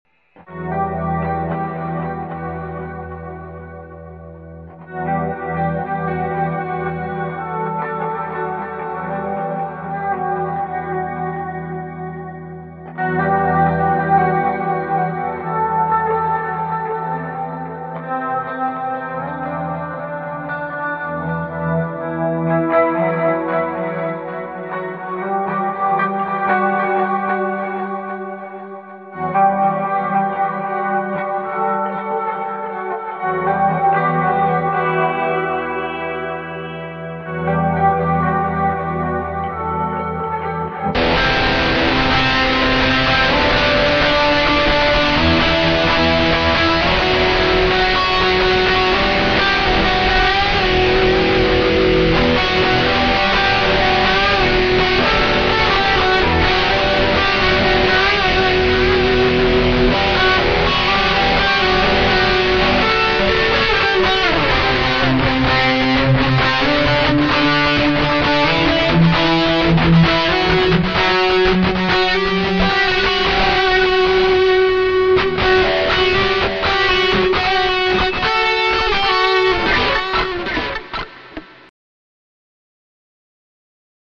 There is no title its just an idea i had that i wanted to put down so i wouldnt forget it. I couldnt get the trasition between the begining part and the heaver part to sound the way i wanted it to but its a rough idea!